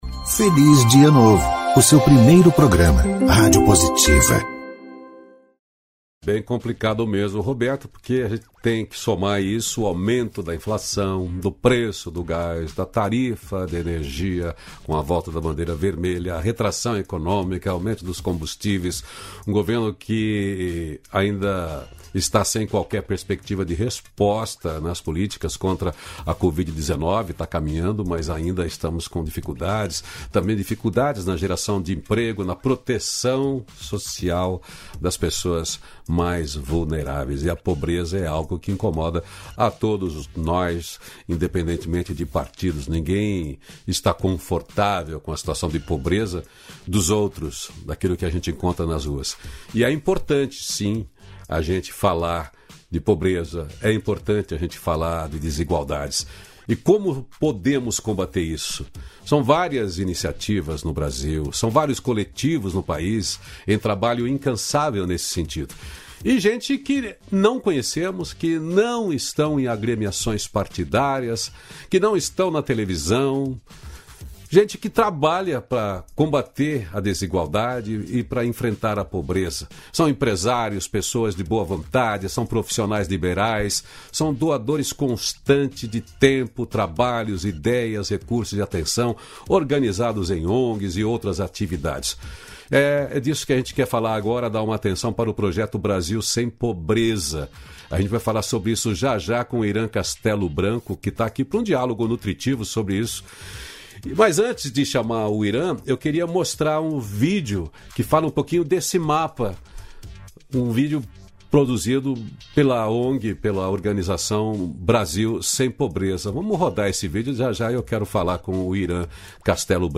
244-feliz-dia-novo-entrevista.mp3